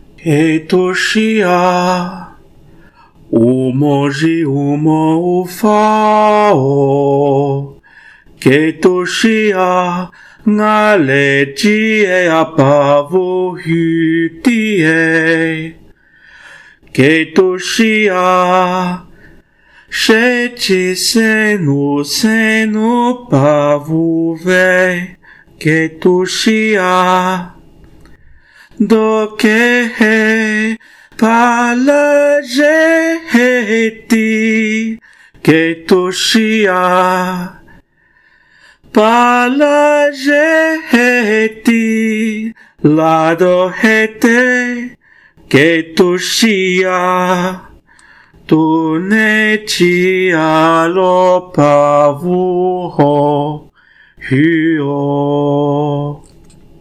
Recording of "Këtûshí â" poem in Mêlázêla